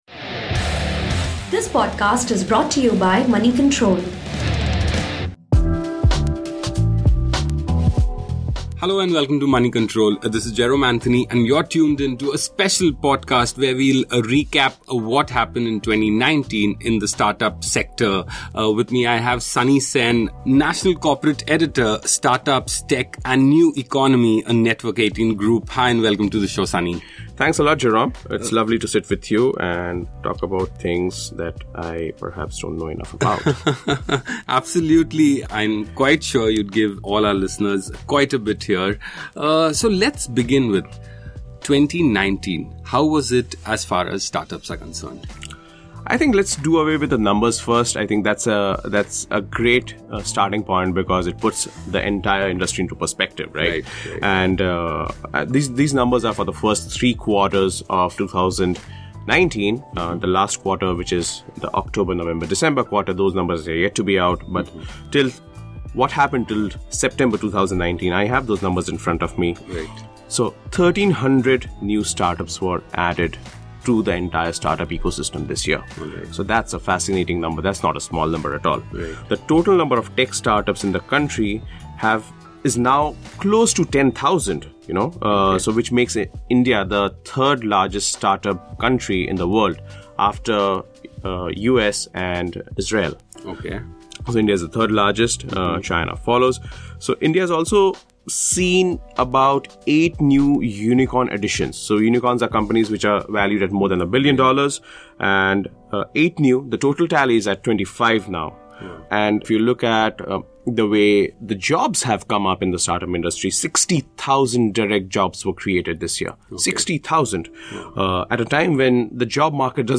gets in conversation with